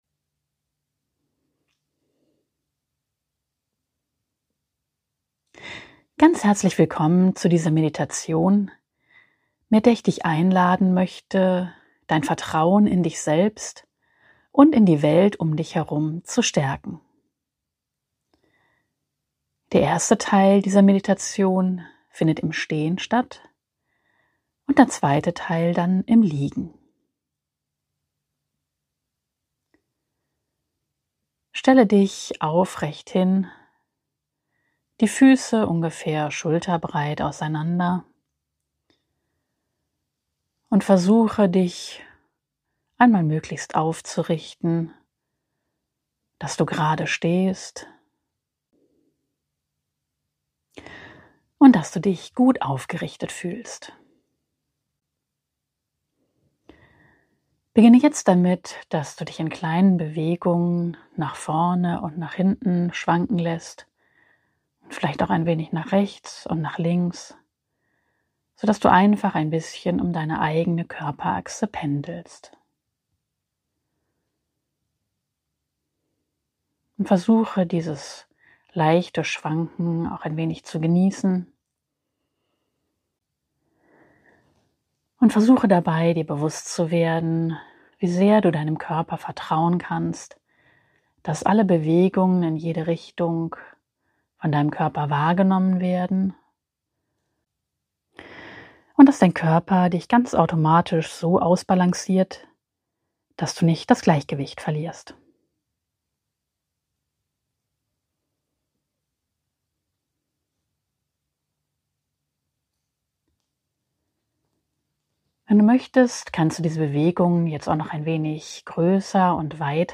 Persönliche Meditation